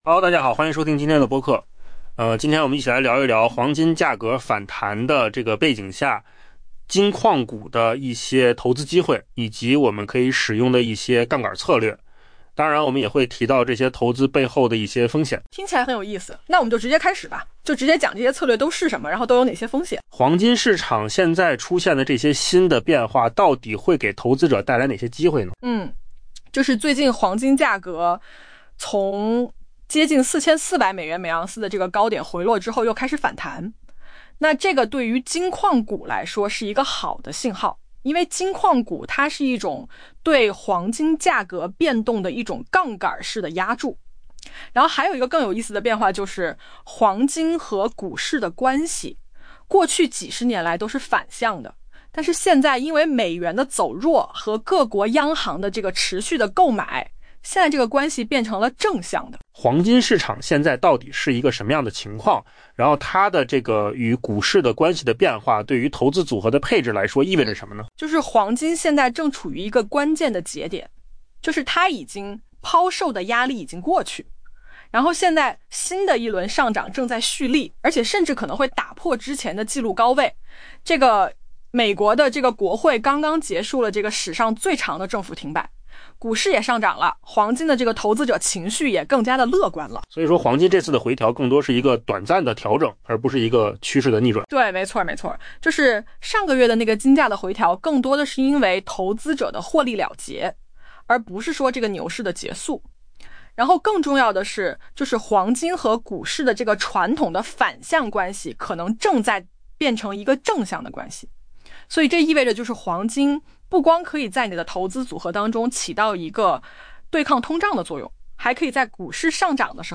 AI 播客：换个方式听新闻 下载 mp3 音频由扣子空间生成 尽管金价较不到一个月前接近 4400 美元/盎司的高点有所回落，但目前正开始反弹。